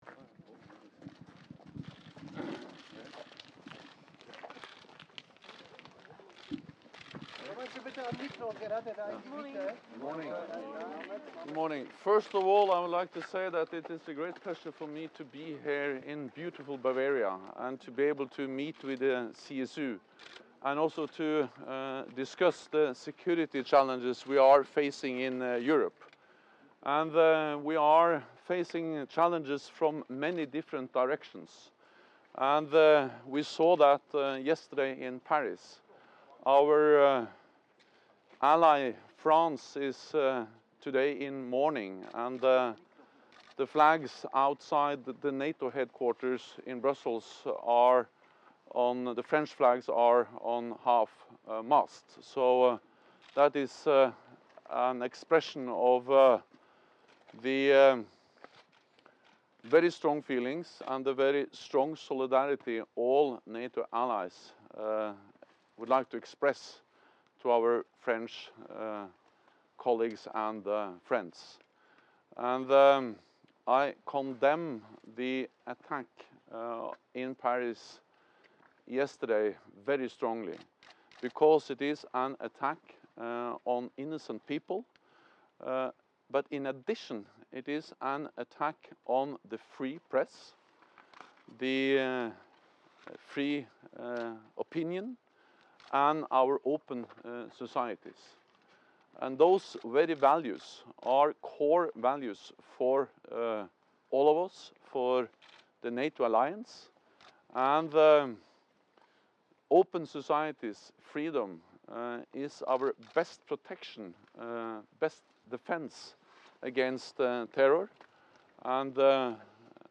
Doorstep statement by NATO Secretary General Jens Stoltenberg at the CSU conference in Wildbad Kreuth, Germany
Doorstep statement by NATO Secretary General Jens Stoltenberg upon his arrival at the Bavarian CSU annual meeting